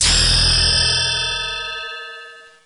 Everquest level up ding
everquest-level-up-ding.mp3